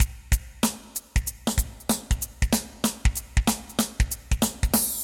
2-DRUMS / 95-BPM
DRUM-089.mp3